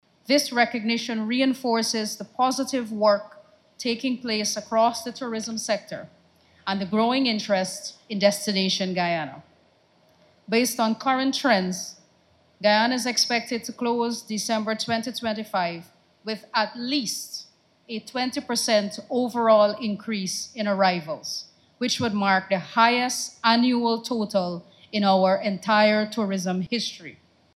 Minister of Tourism, Susan Rodrigues